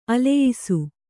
♪ aleyisu